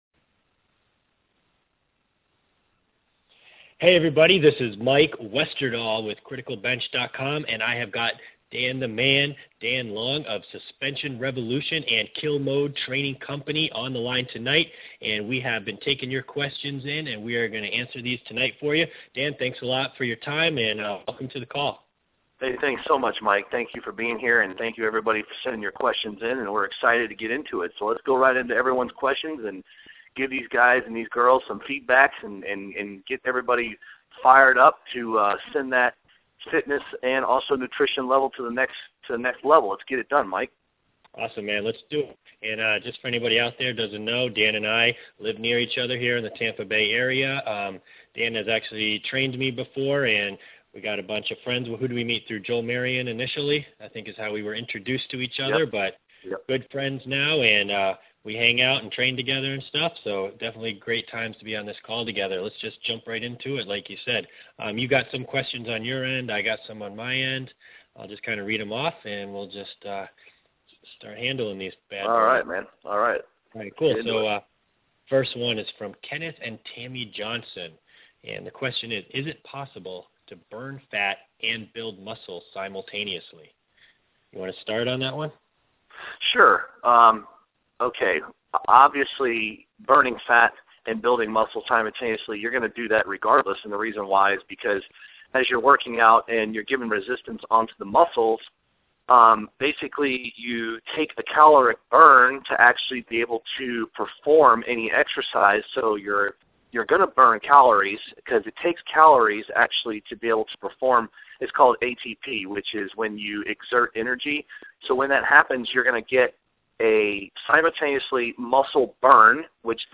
Coaching Call Replay Download Link [free mp3]